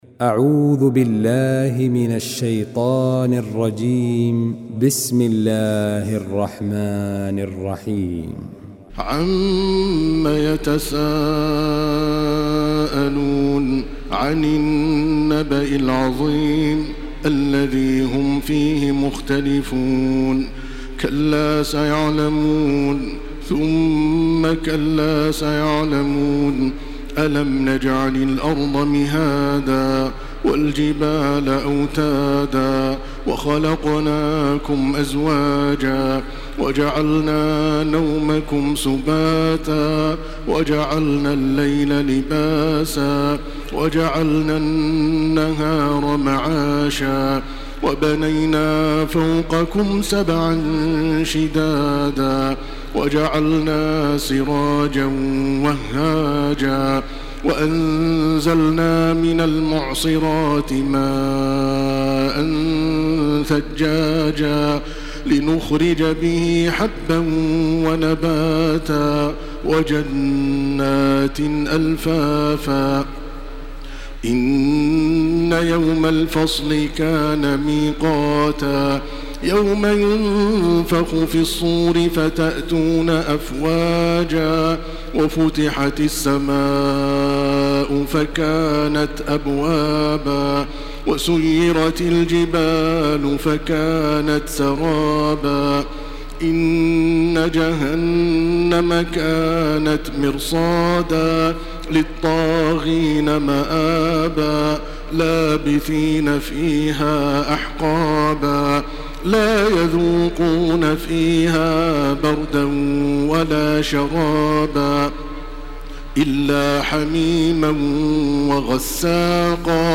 Makkah Taraweeh 1429
Murattal